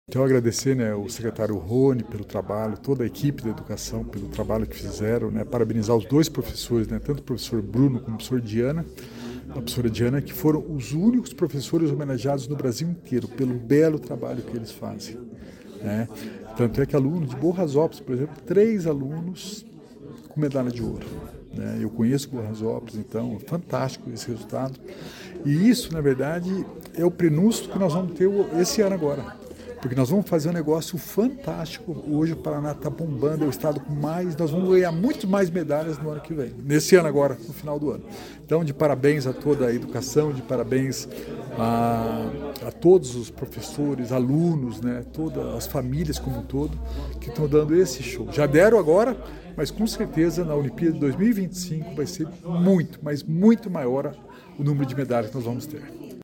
Sonora do secretário da Inovação e Inteligência Artificial, Alex Canziani, sobre a entrega de medalhas da Olimpíada Brasileira de Inovação, Ciência e Tecnologia para alunos da rede estadual